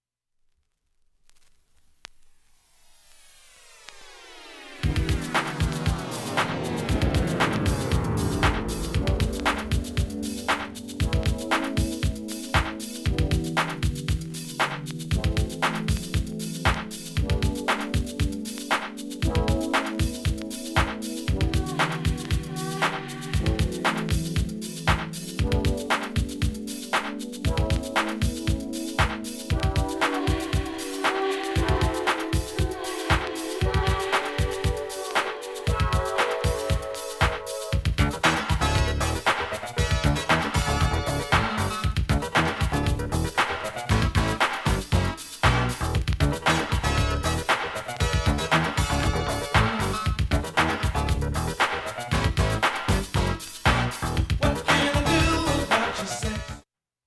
盤面きれいで音質良好全曲試聴済み。
５０秒の間に周回プツ出ますがかすかです。
後半は聴き取り出来ないレベルです。